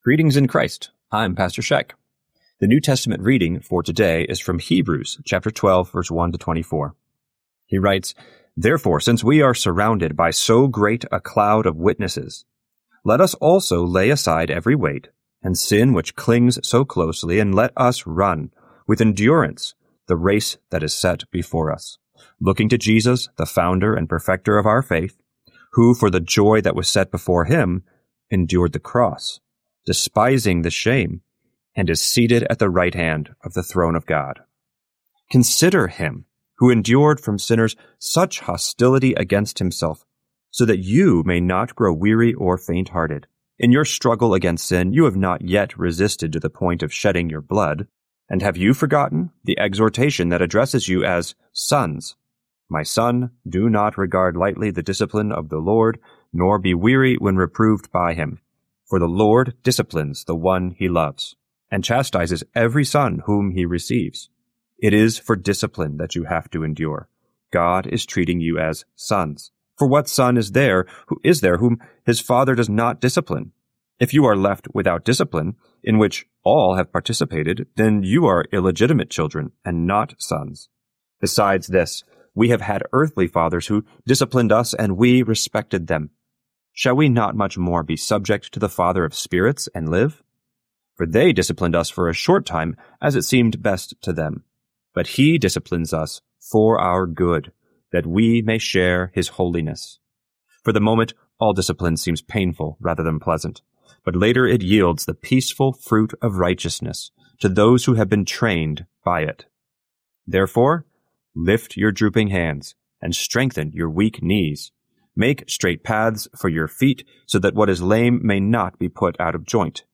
Morning Prayer Sermonette: Hebrews 12:1-24
Hear a guest pastor give a short sermonette based on the day’s Daily Lectionary New Testament text during Morning and Evening Prayer.